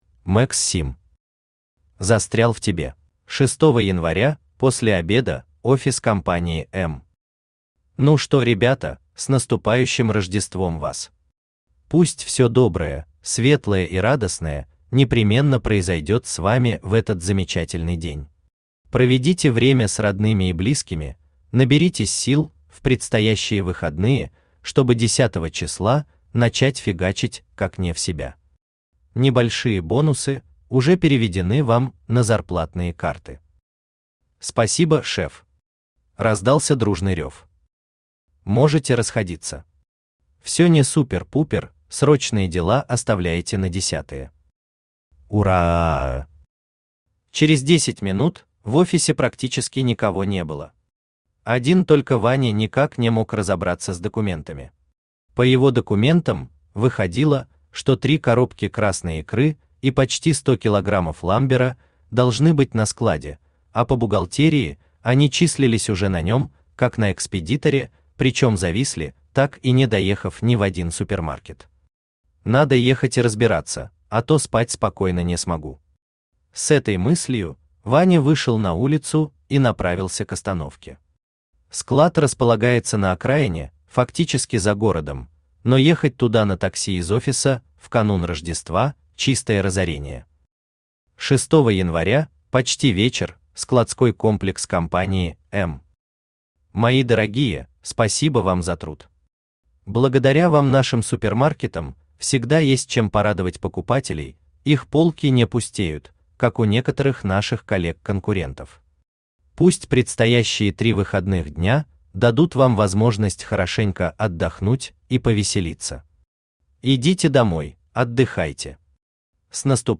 Аудиокнига Застрял в тебе | Библиотека аудиокниг
Aудиокнига Застрял в тебе Автор Max Sim Читает аудиокнигу Авточтец ЛитРес.